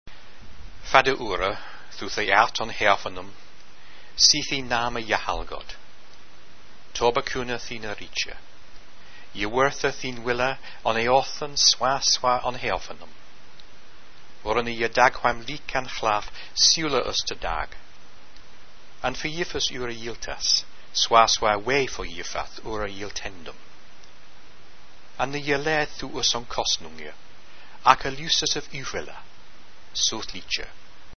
The Lord's Prayer sounded about 1000 years ago